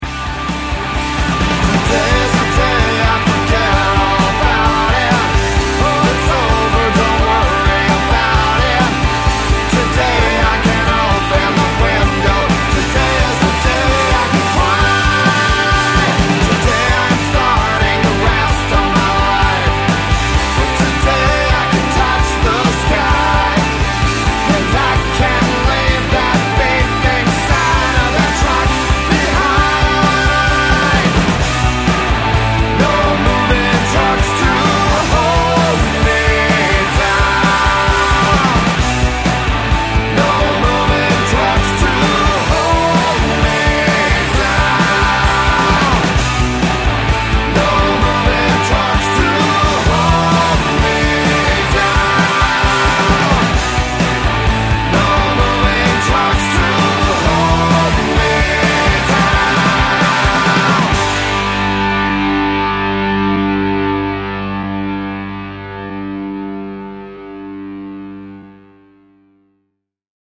G Major, 130 BPM